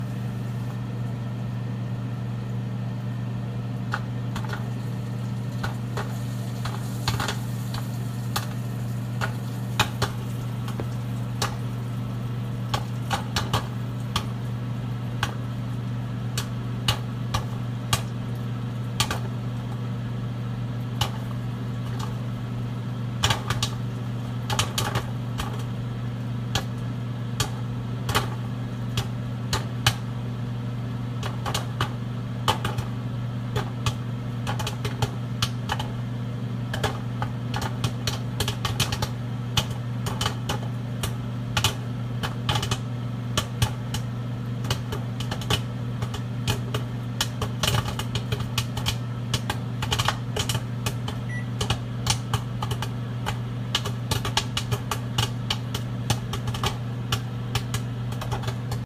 爆炸和噼啪声
描述：冰解冻和开裂的声音，非常适合悬浮的冰河场景。
Tag: OWI 河流 吱吱 持久性有机污染物 冰裂纹 冰川 crackly